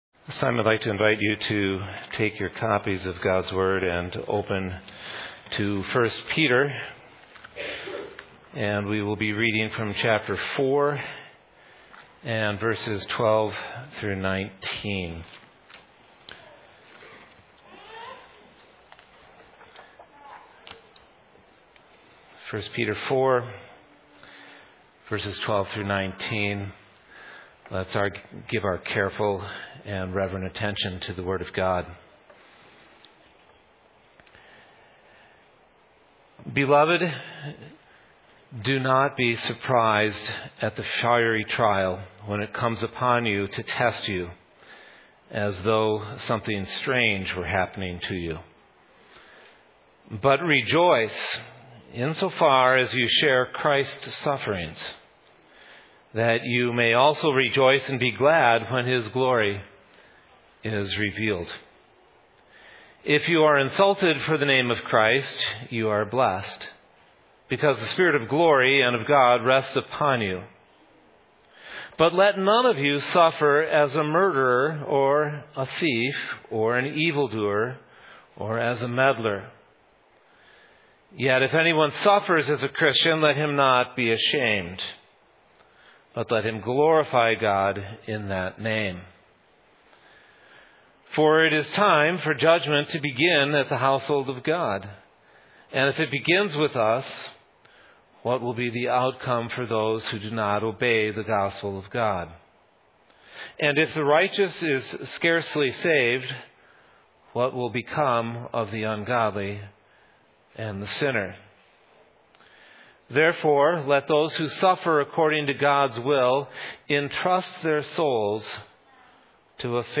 Passage: Hebrews 1:1-4 Service Type: Evening